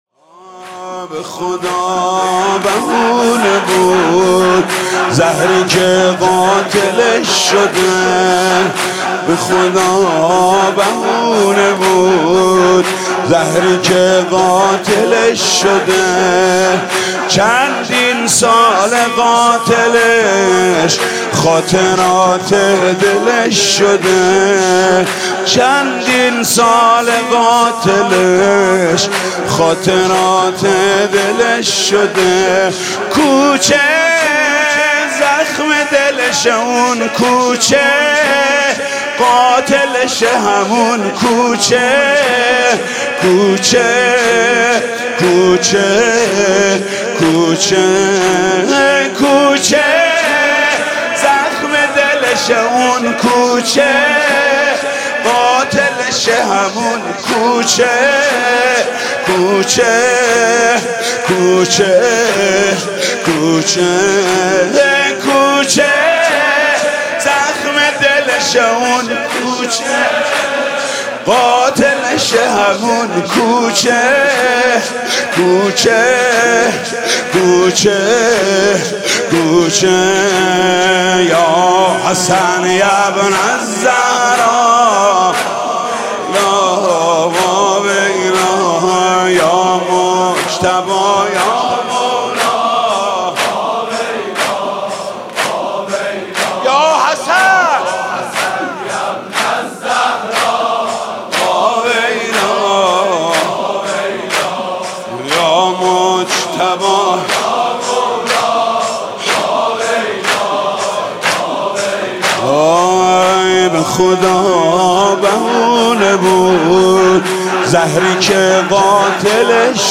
«شهادت امام حسن 1393» زمینه: به خدا بهونه بود زهری که قاتلش شده